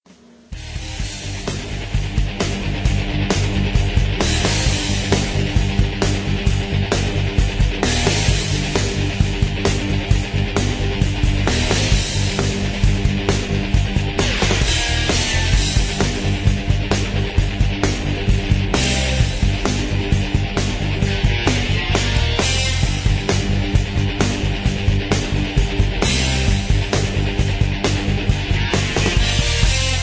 karaoke, mp3 backing tracks